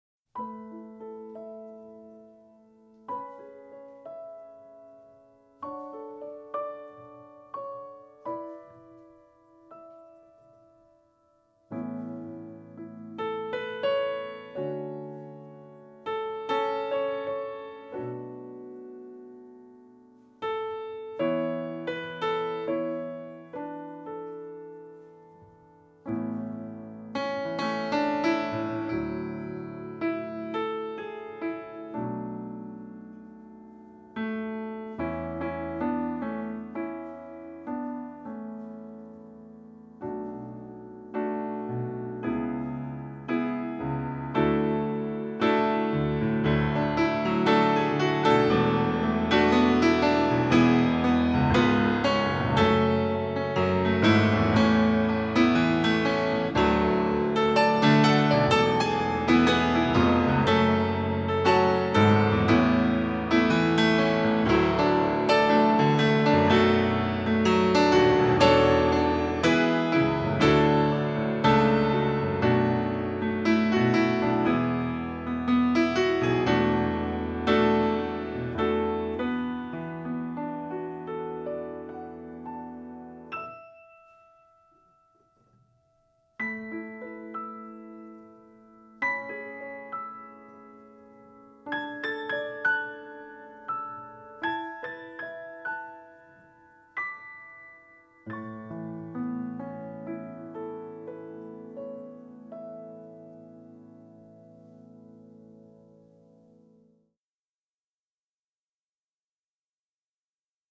Performance Tracks